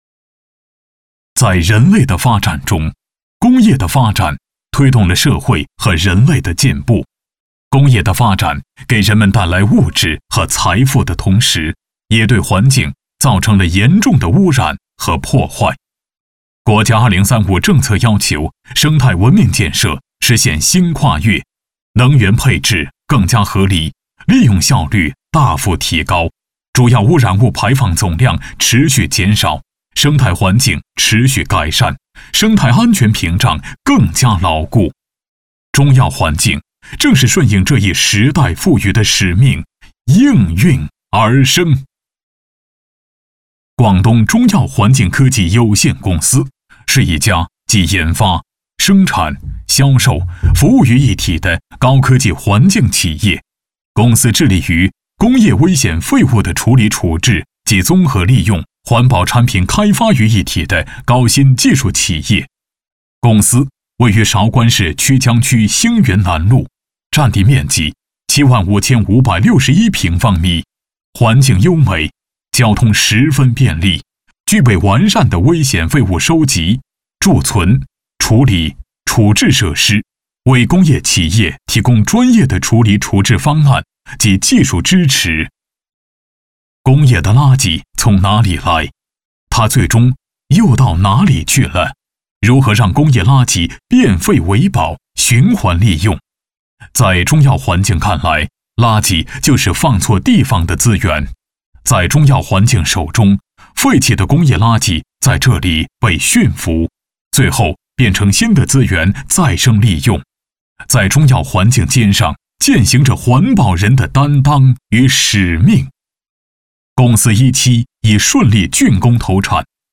209男-工作汇报
语言：普通话 （209男）
特点：大气浑厚 稳重磁性 激情力度 成熟厚重
风格:大气配音